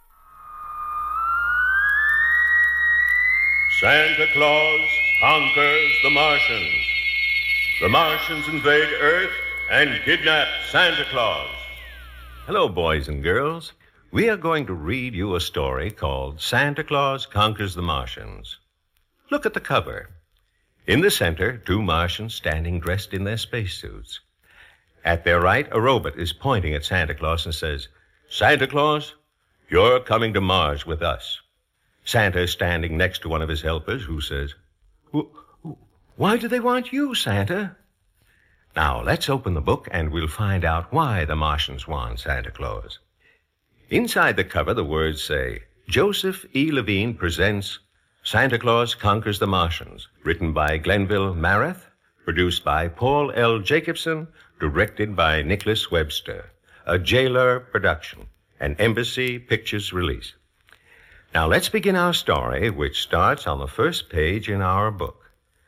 SPECIAL NOTE: These recordings were originally taken from analog masters and music & effects tracks and therefore certain limitations from the source tapes may be evident.
13 Read-Aloud-Book Part1.mp3